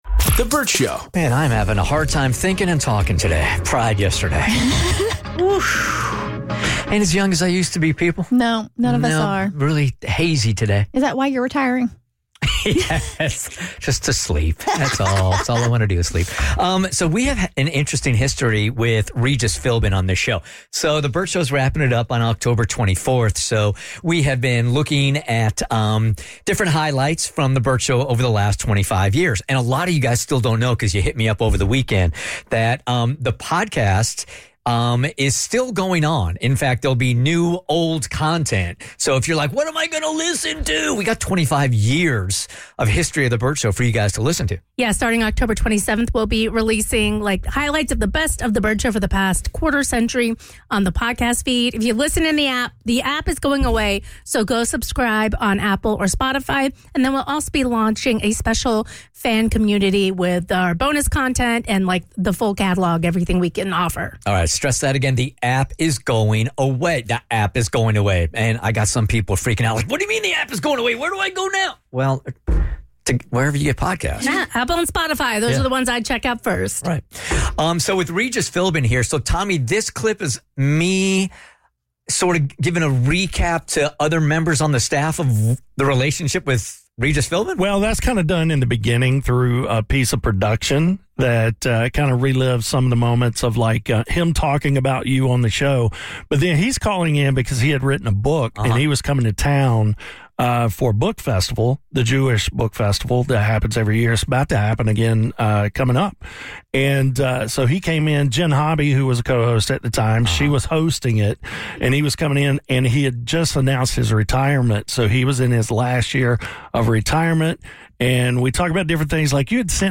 Icon Regis Philbin Called in RIGHT before his retirement!